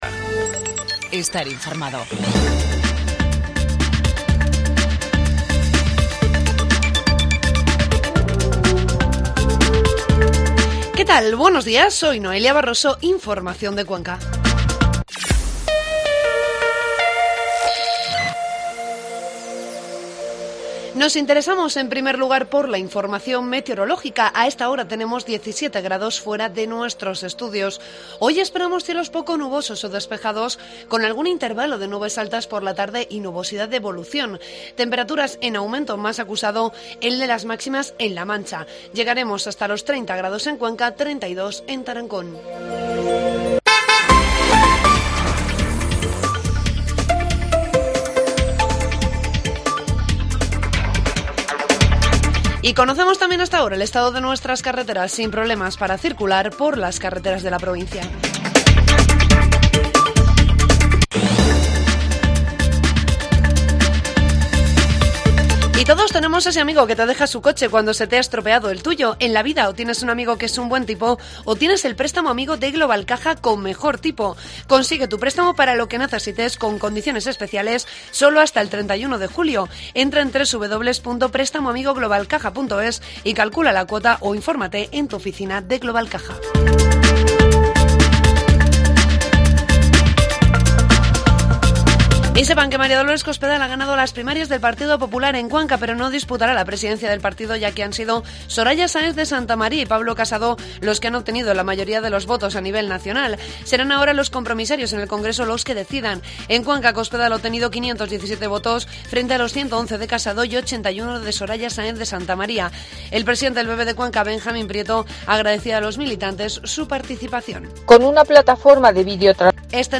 AUDIO: Informativo matinal 6 de julio.